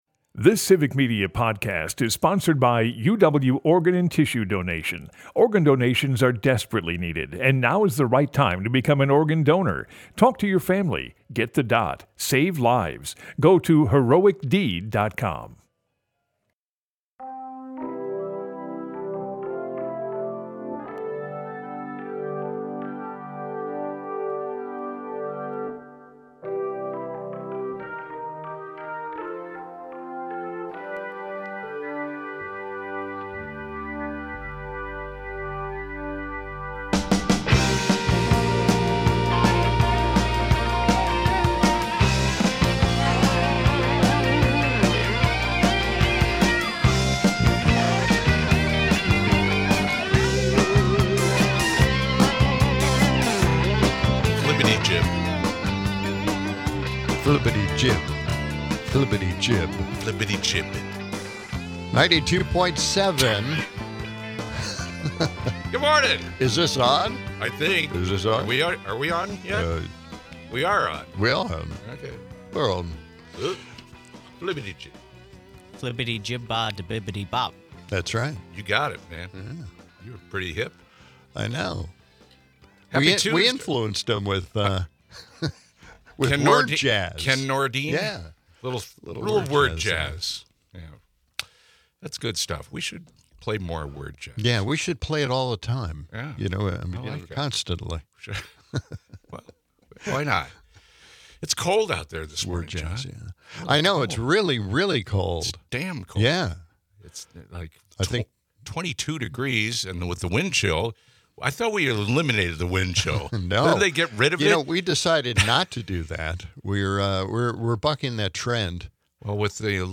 We also hear from Sarah McBride, the target of the attacks by Mace, about taking the high road, and trying to focus on the real issues her constituents face.